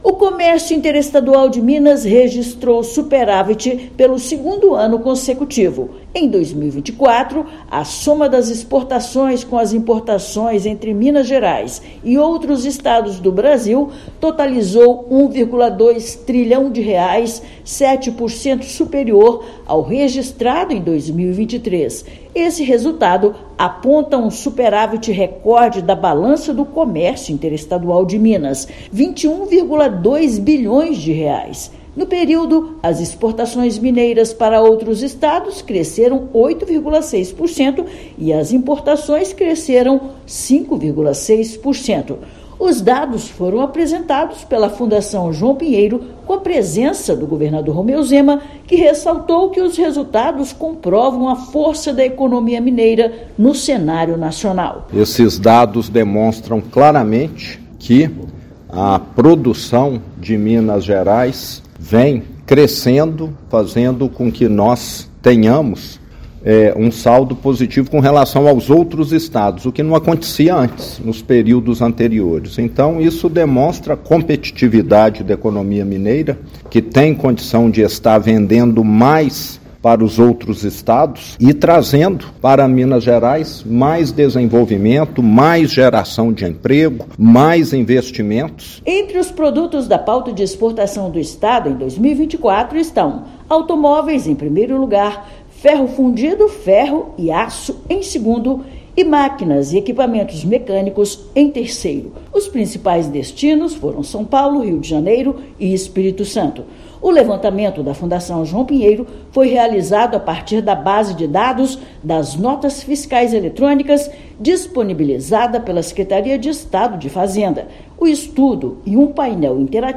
Vendas cresceram 8,6% e compras aumentaram 5,3%, em segundo desempenho positivo na série histórica; São Paulo foi o principal parceiro comercial no período. Ouça matéria de rádio.